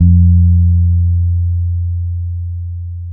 -MM DUB  F 3.wav